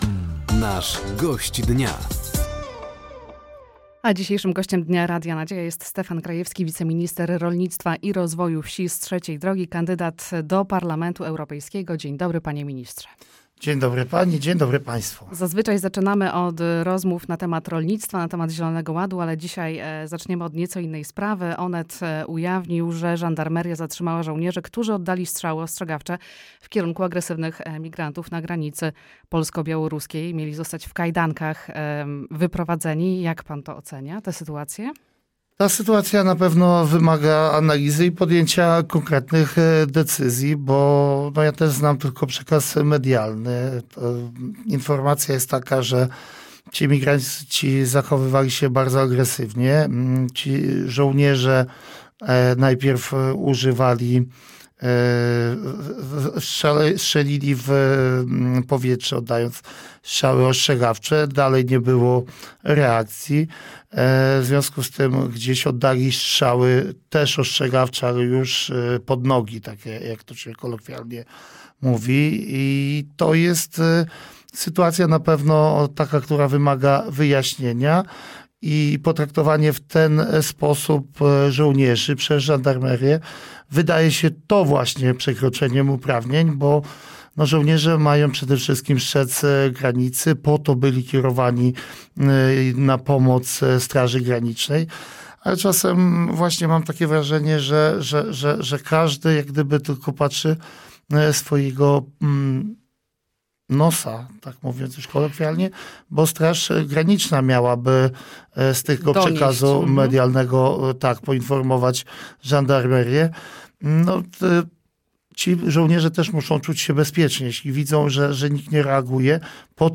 Gościem Dnia Radia Nadzieja był Stefan Krajewski, Wiceminister Rolnictwa i Rozwoju Wsi.